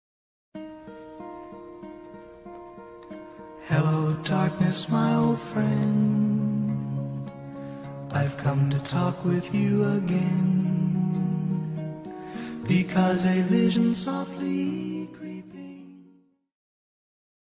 Грустная музыка из мема про фейл